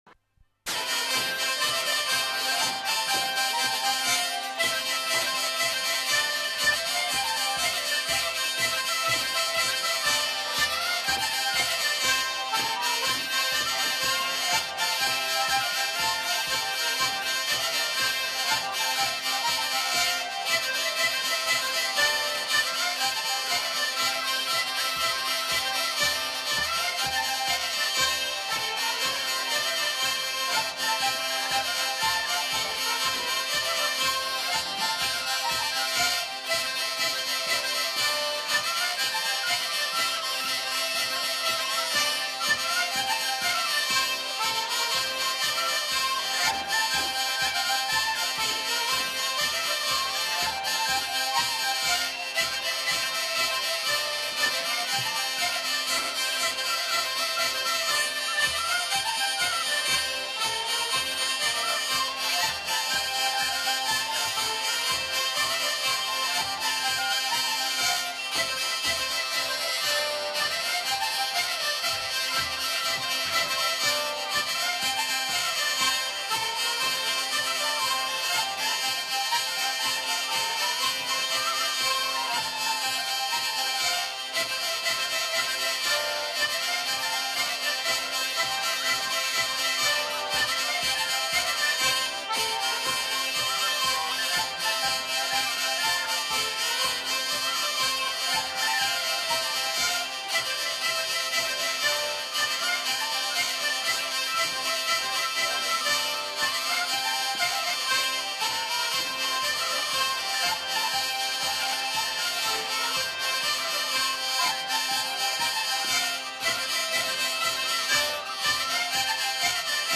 Aire culturelle : Gabardan
Lieu : Houeillès
Genre : morceau instrumental
Instrument de musique : vielle à roue ; accordéon diatonique ; violon ; flûte à bec
Danse : rondeau